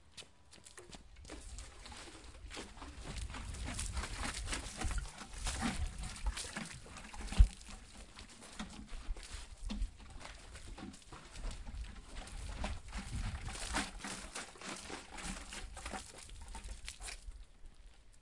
Run and Splash 01
描述：Running and then splashing water out of a garden pitcher. Meant to sound like splashing gas from a gas can.
标签： run splash gas water
声道立体声